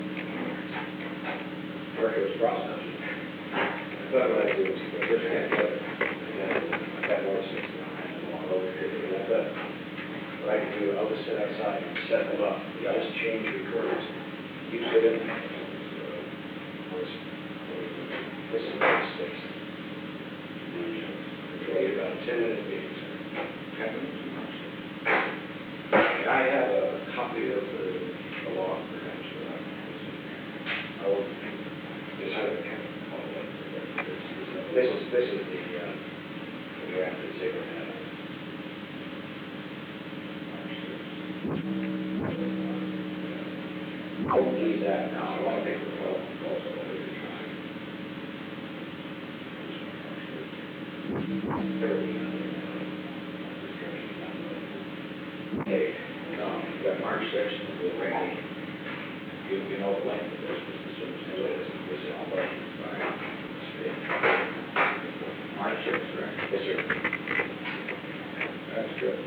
Secret White House Tapes
• President Richard M. Nixon
Conversation No. 442-31
Location: Executive Office Building